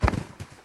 zombie_falling_1.mp3